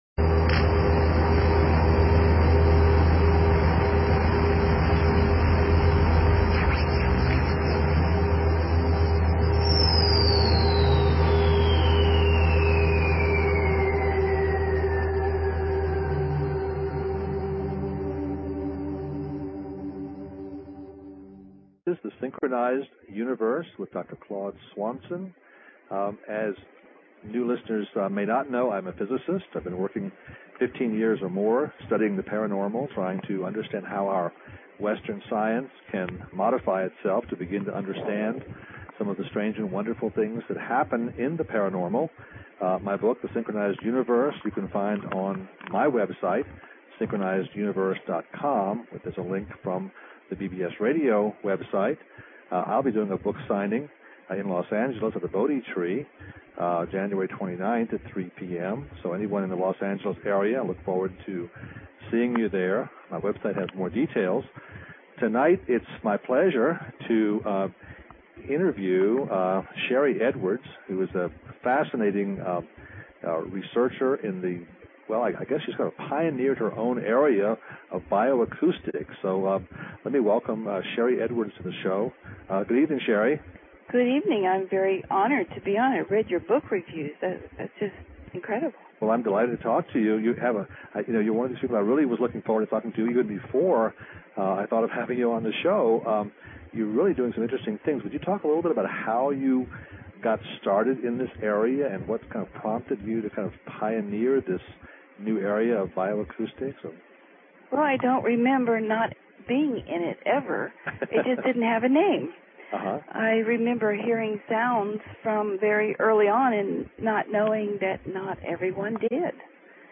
Talk Show Episode, Audio Podcast, Synchronized_Universe and Courtesy of BBS Radio on , show guests , about , categorized as